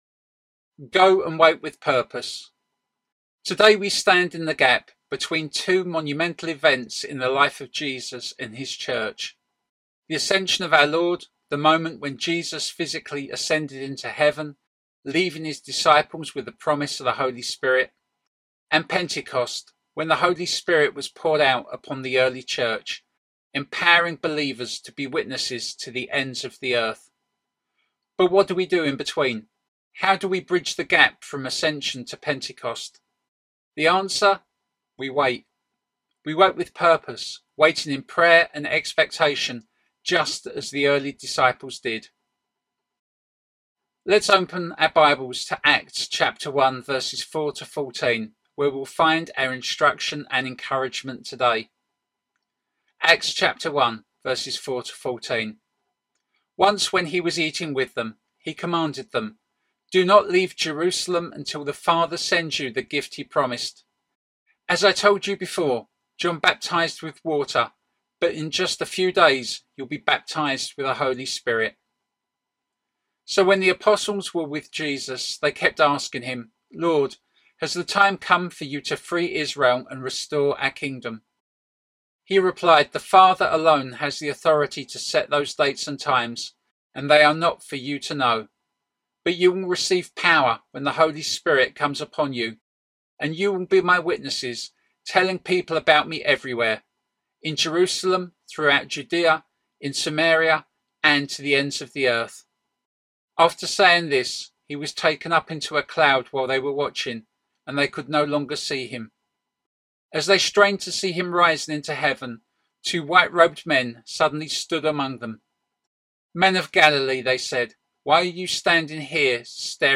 'Go! And Wait with Purpose' at Estuary Elim Online.